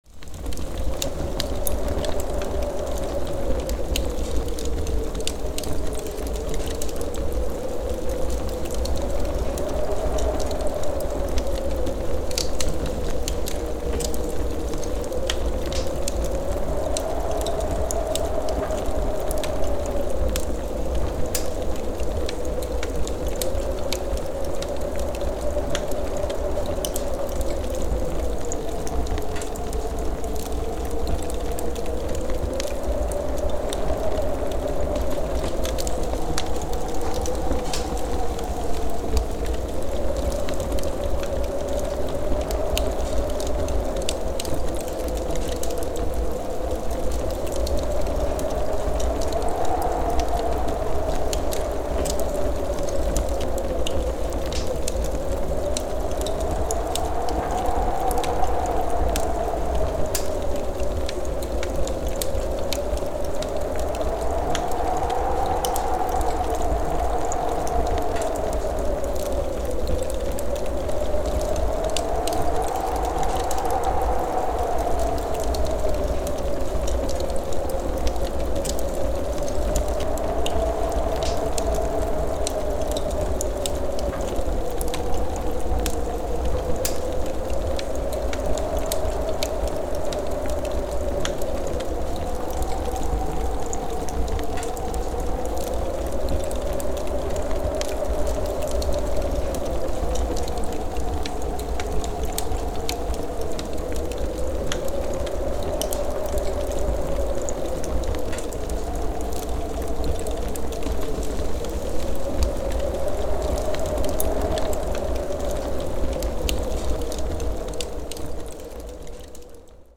Blizzard Winds And Fireplace Sleep Sounds
The sound of firewood crackling in the fireplace while a cold winter wind blows outside.
A perfect relaxing and cozy sound. Nature sounds.
Genres: Sound Effects
Blizzard-winds-and-fireplace-sleep-sounds.mp3